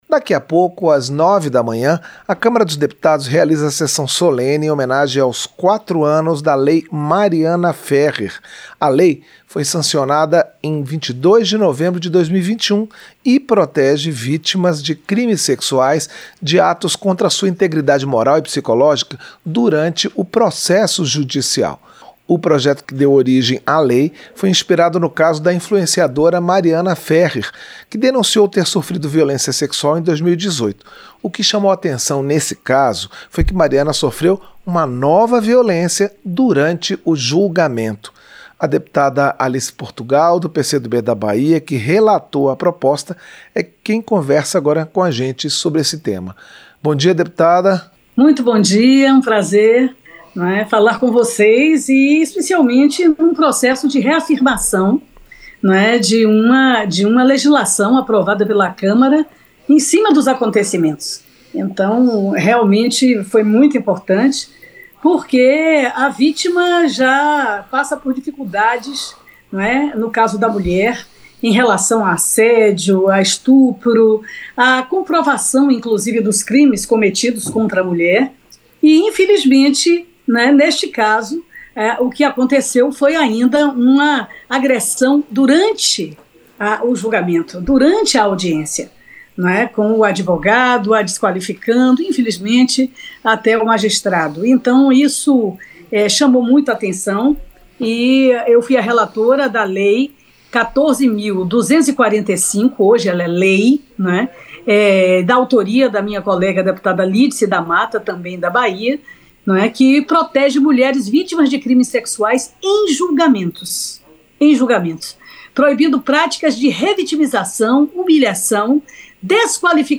• Entrevista - Dep. Alice Portugal (PCdoB-BA)
Programa ao vivo com reportagens, entrevistas sobre temas relacionados à Câmara dos Deputados, e o que vai ser destaque durante a semana.